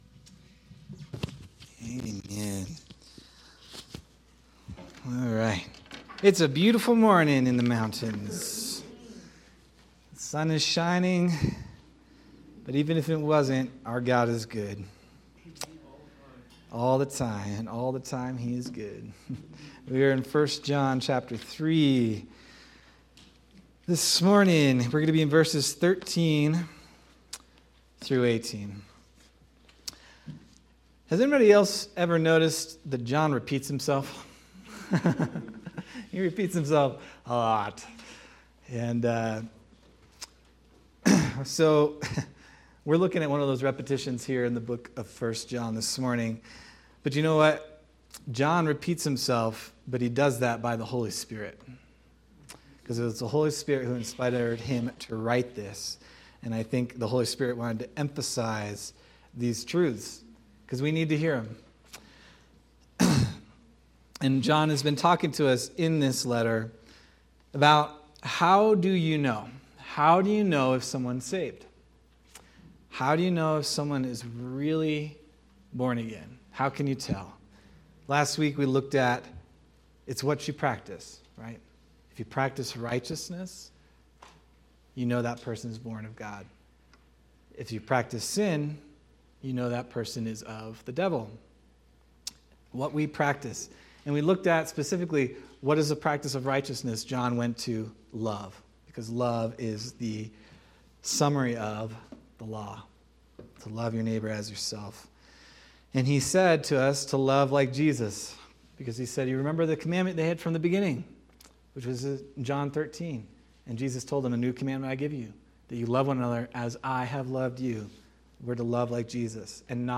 April 19th, 2026 Sermon